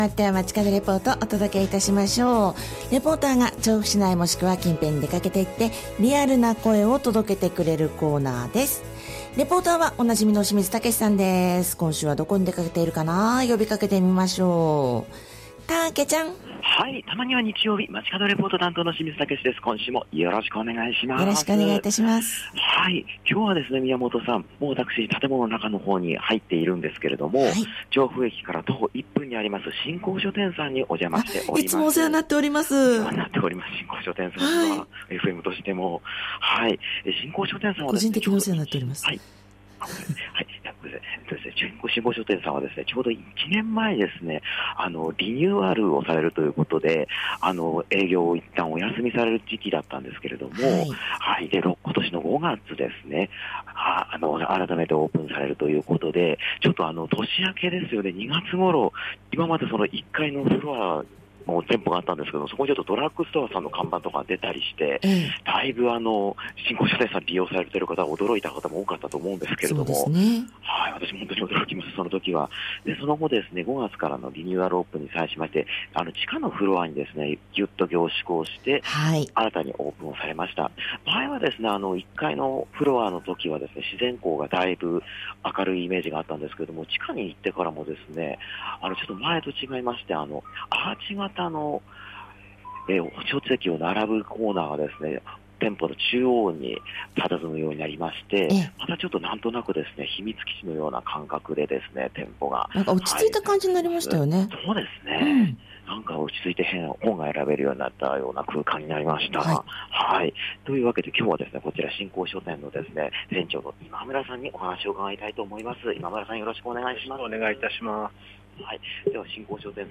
今週も平年よりやや気温が高い中でお届けした本日の街角レポートは、「真光書店」さんからのレポートです！